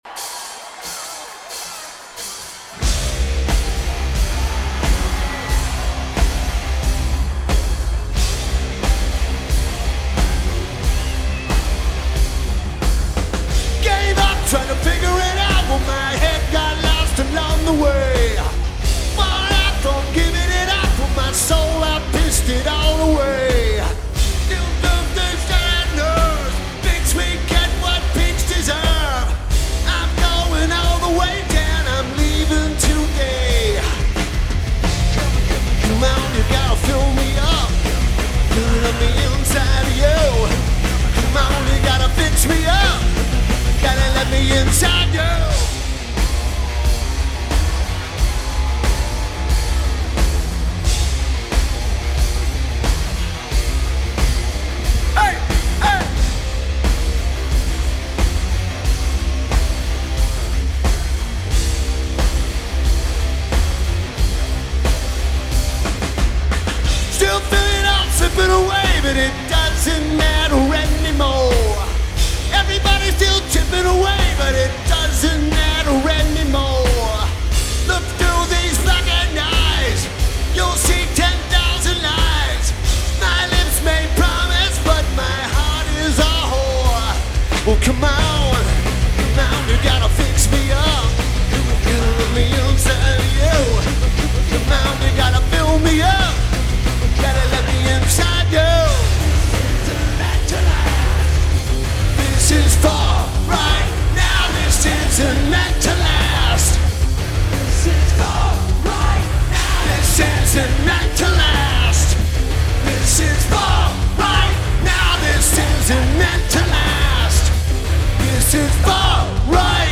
Keyboards/Bass/Backing Vocals
Drums
Guitar